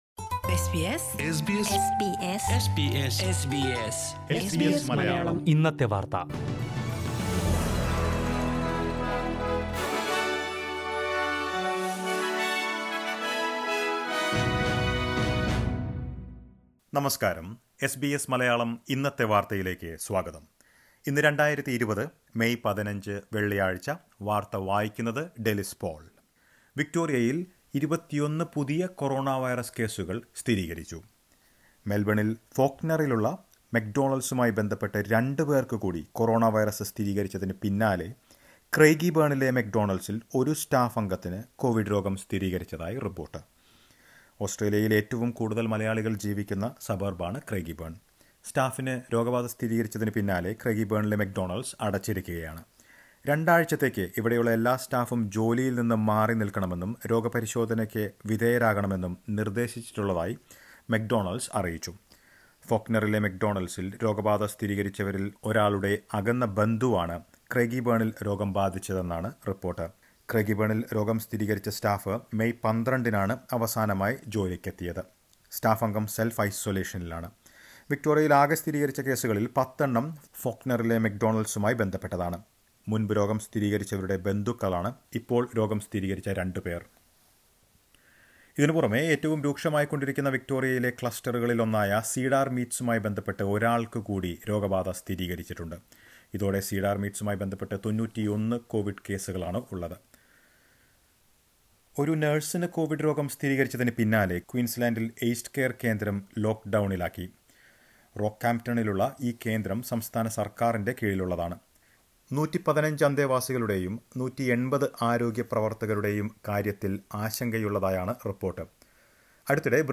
2020 മേയ് 15 ലെ ഓസ്ട്രേലിയയിലെ ഏറ്റവും പ്രധാന വാർത്തകൾ കേൾക്കാം...